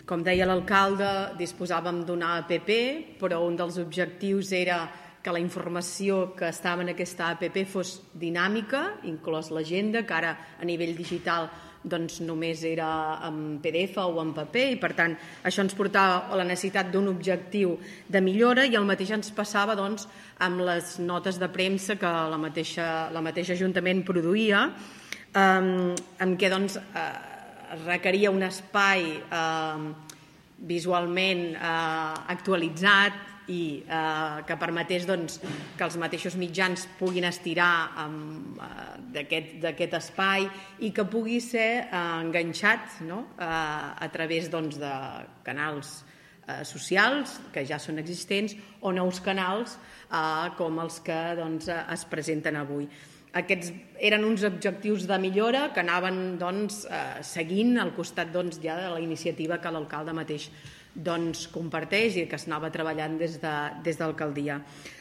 La regidora de patrimoni marítim i local i impulsora del projecte, Maria Puig, destacava que a part de millorar la comunicació amb la ciutadania, aquestes dues eines també havien de permetre dinamitzar els canals de comunicació ja existents del consistori.